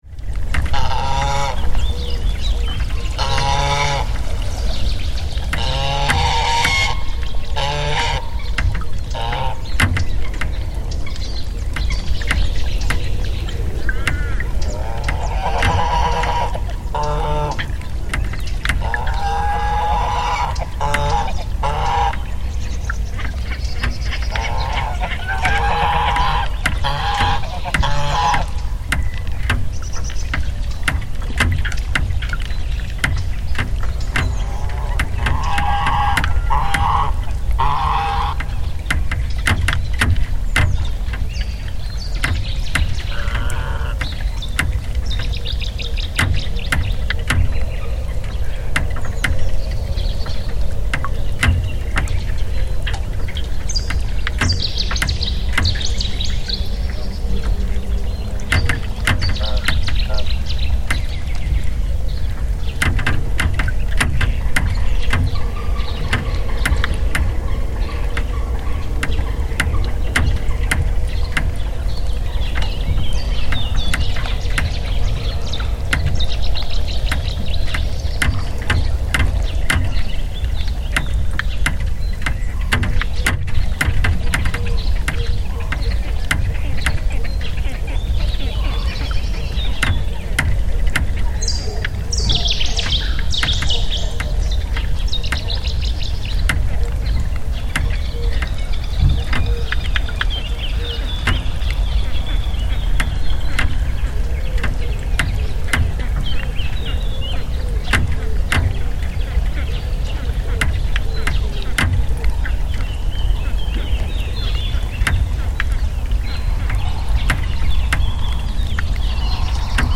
Floating dock on Lake Chiusi
The recording is from April 7, 2025 and was made at Lake Chiusi located in the Val di Chiana in the province of Siena, Tuscany. A small floating dock was chosen as the audio recording point. The banging of the dock accompanies the listening of the lake soundscape. It is the lake itself that, with its movement, 'plays' this dock. The random rhythm generated is grafted onto the very rich acoustic environment of the lake.